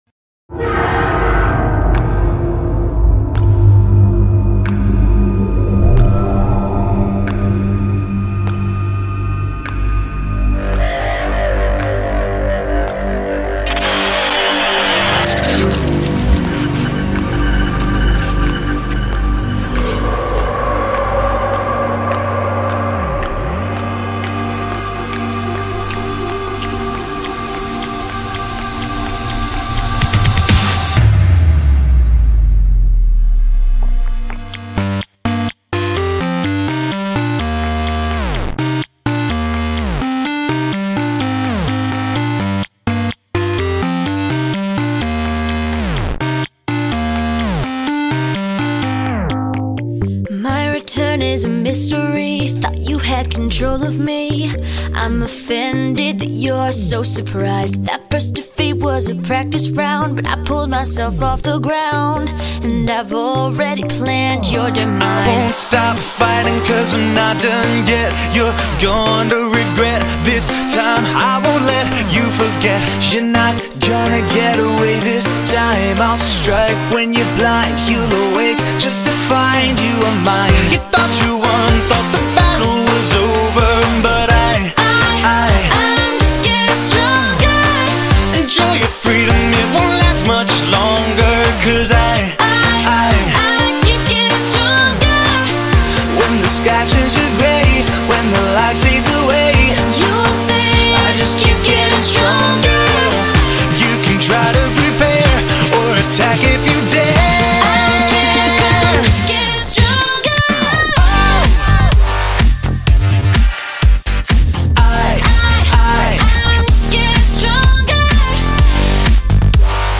A villain song